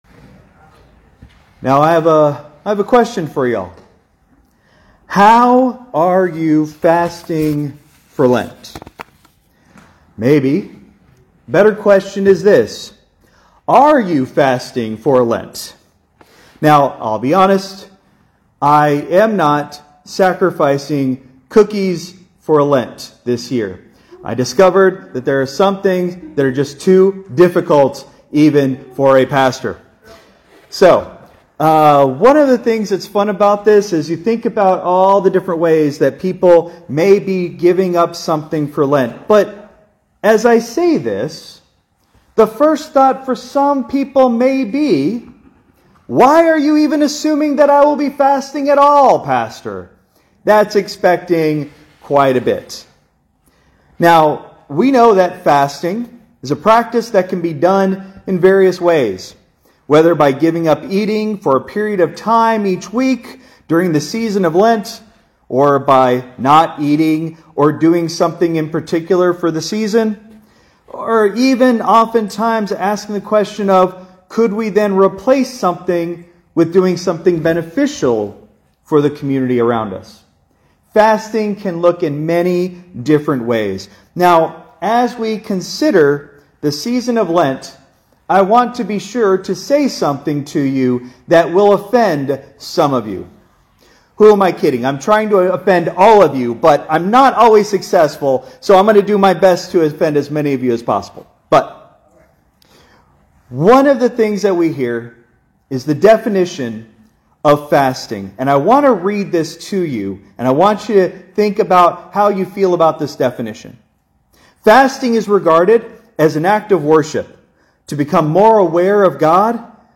Christ Memorial Lutheran Church - Houston TX - CMLC 2025-03-05 Sermon (Ash Wednesday)